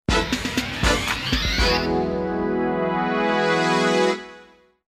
2. Новости середины часа. Финальная отбивка: